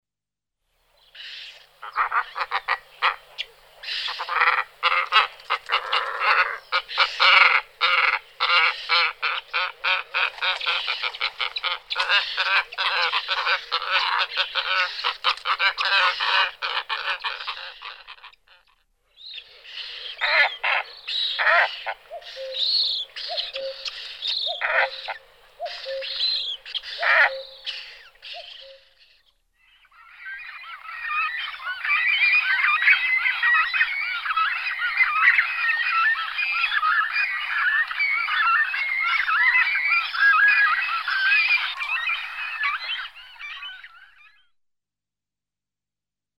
60a.Glossy Ibis.mp3